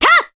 Scarlet-Vox_Attack2_Jp.wav